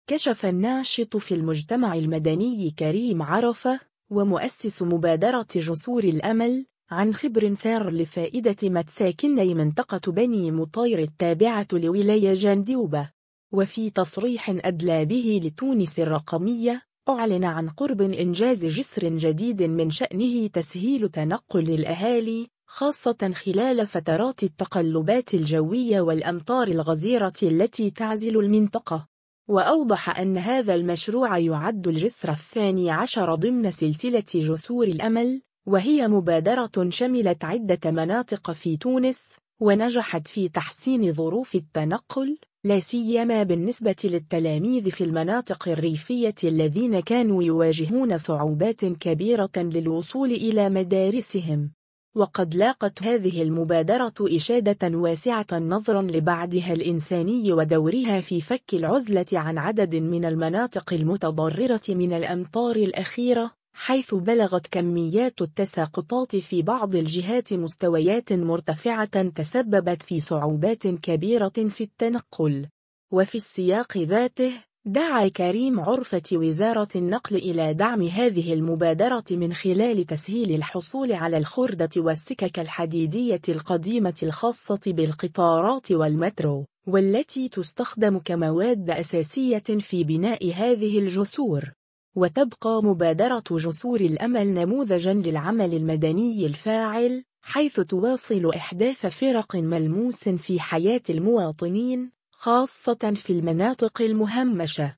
وفي تصريح أدلى به لتونس الرقمية، أعلن عن قرب إنجاز جسر جديد من شأنه تسهيل تنقل الأهالي، خاصة خلال فترات التقلبات الجوية والأمطار الغزيرة التي تعزل المنطقة.